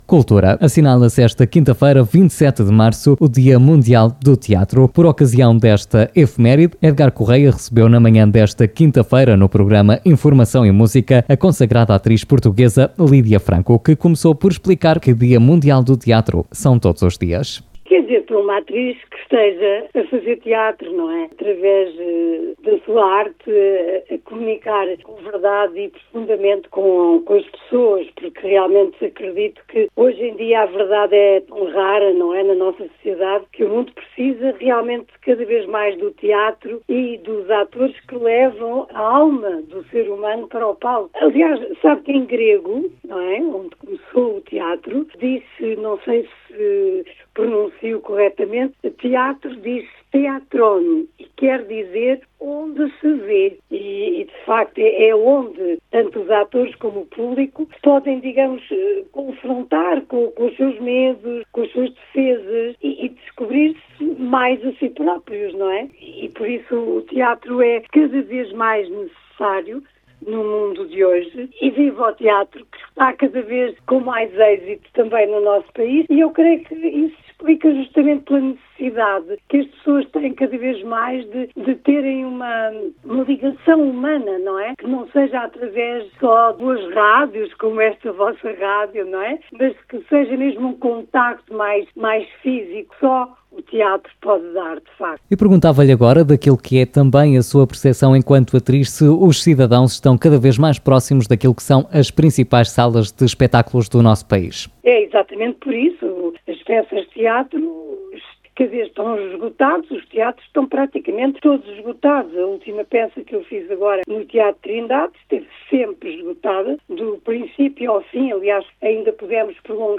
Escute, aqui, as declarações da atriz Lídia Franco à Rádio Marinhais: